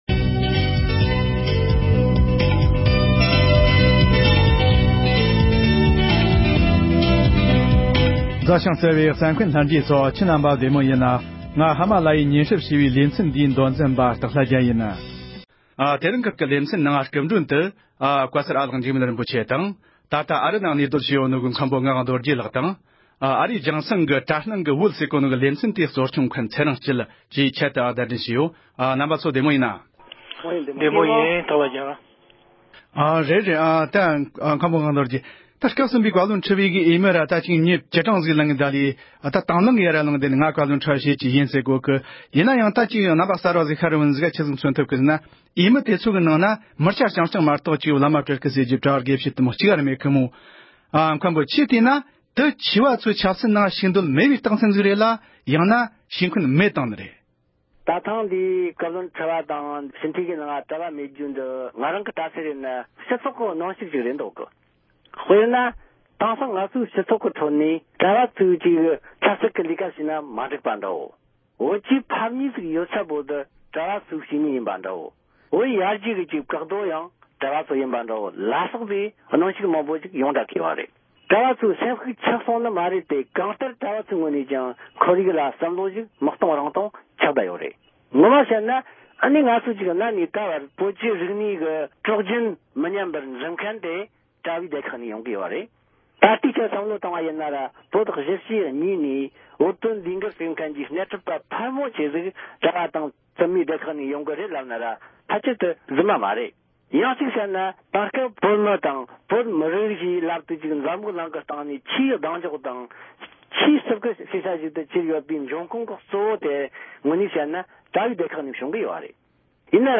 སྐབས་གསུམ་པའི་བཀའ་བློན་ཁྲི་པའི་འོས་མི་ནང་བླ་སྤྲུལ་དང་གྲྭ་པ་དགེ་བཤེས་སོགས་གཅིག་ཀྱང་ཞུགས་མེད་པའི་སྐོར་བགྲོ་གླེང༌།
སྒྲ་ལྡན་གསར་འགྱུར།